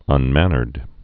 (ŭn-mănərd)